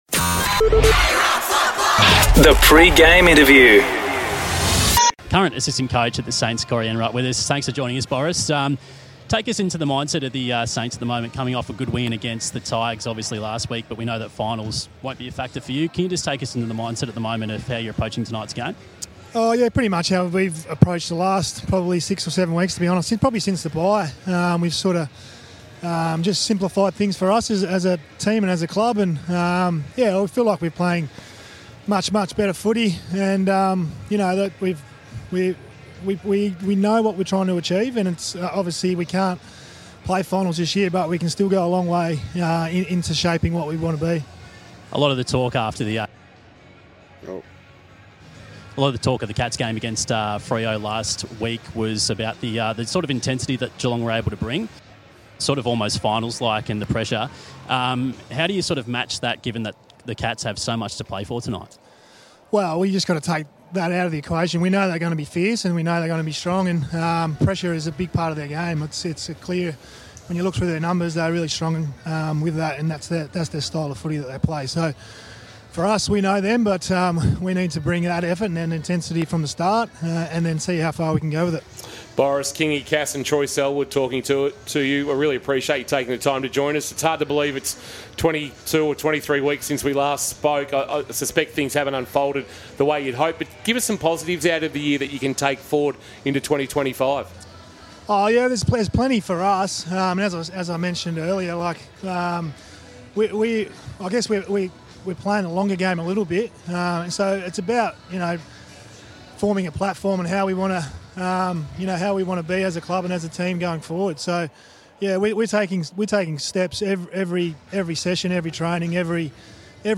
2024 - AFL - Round 23 - St Kilda vs. Geelong - Pre-match interview: Corey Enright (St Kilda Assistant)